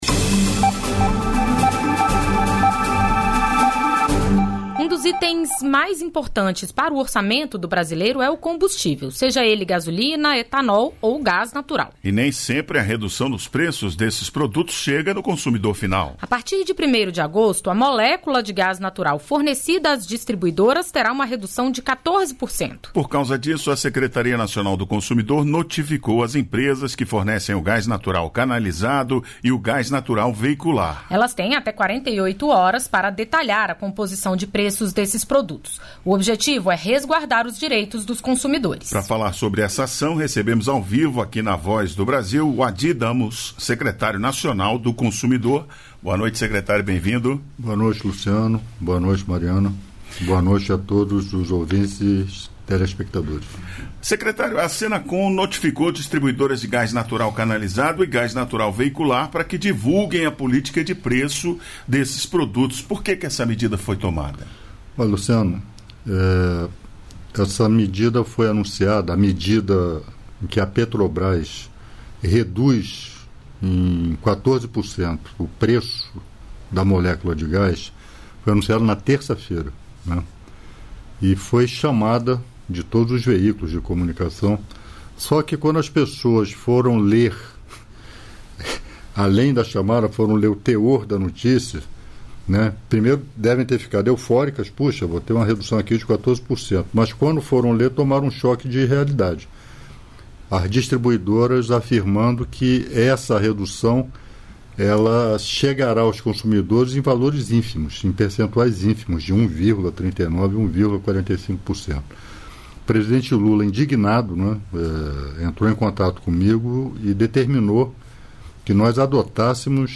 Para apoiar e valorizar o mercado do cinema nacional, um decreto regulamentou a cota de tela, que determina um percentual mínimo de exibição de filmes brasileiros. Nessa entrevista, Márcio Tavares explica as mudanças.